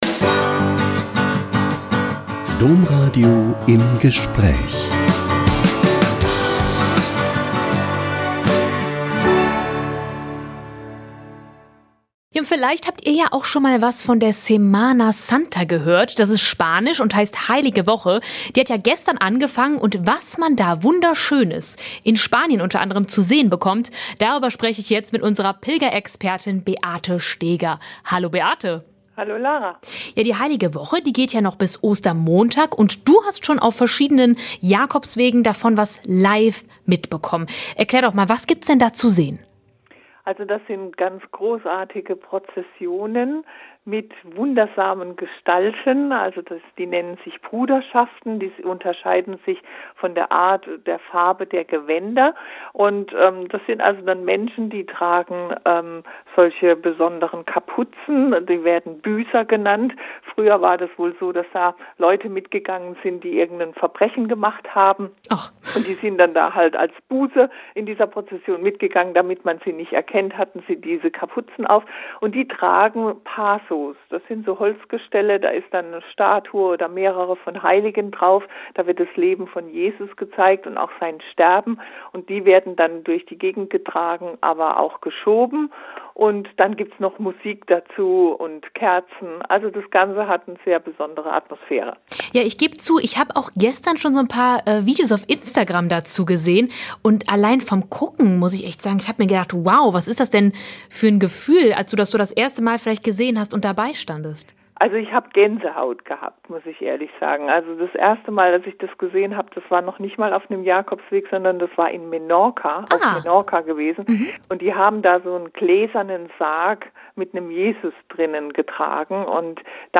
Pilgerexpertin berichtet von prunkvollen Prozessionen in Spanien